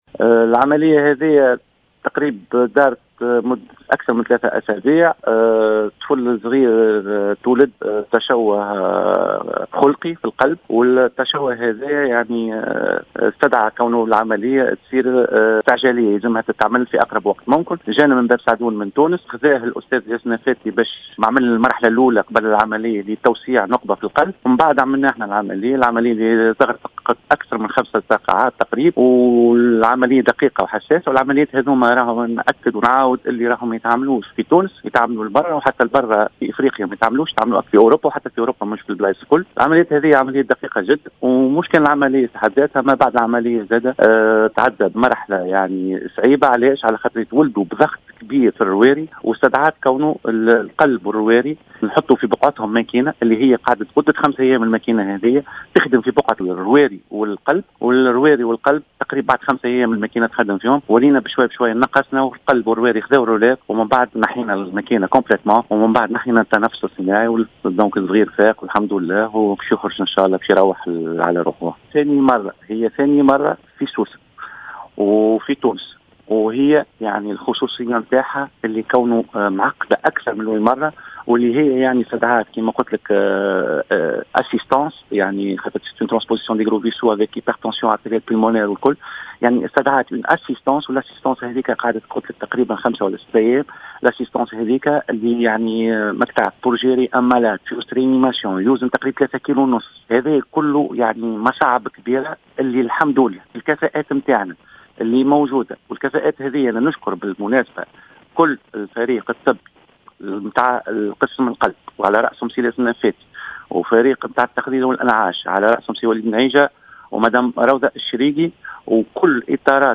وفي تصريح للجوهرة أف أم